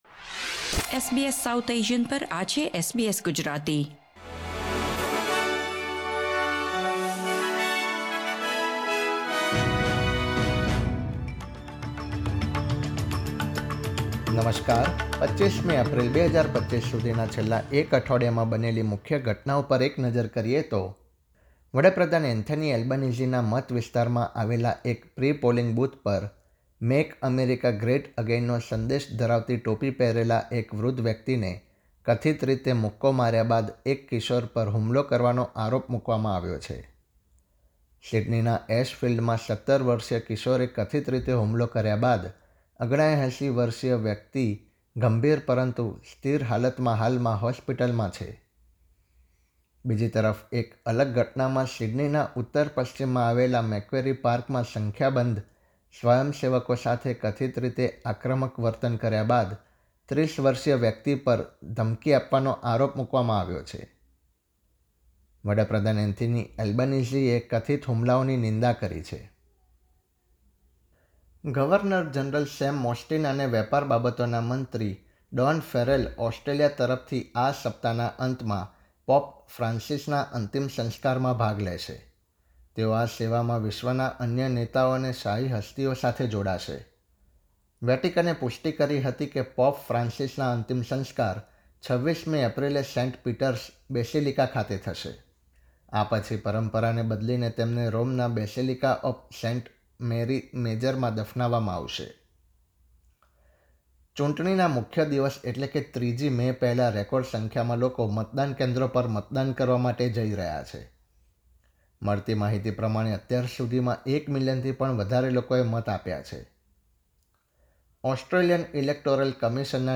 Weekly News Wrap SBS 2025